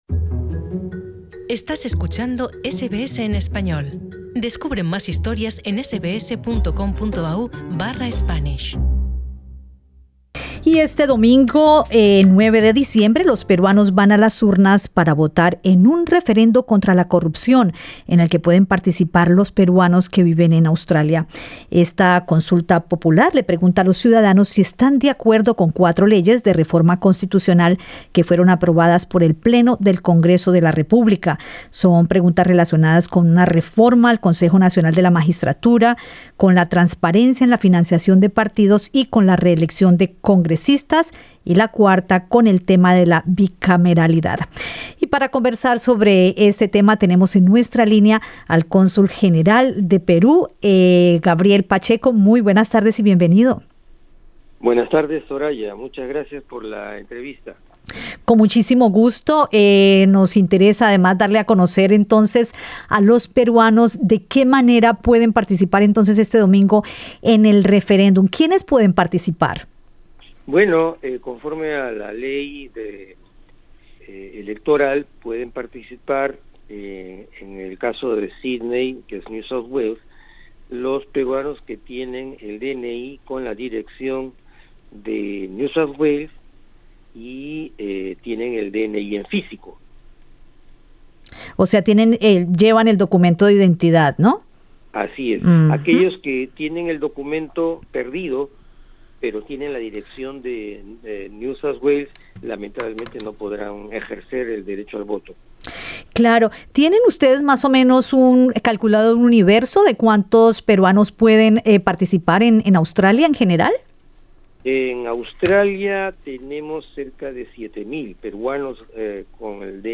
El cónsul general de Perú, Gabriel Pacheco, explicó los puntos que se les preguntan a los peruanos en el referendo anticorrupción que tiene lugar el 9 de diciembre, en entrevista con SBS Spanish (SBS en Español).